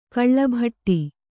♪ kaḷḷabhaṭṭi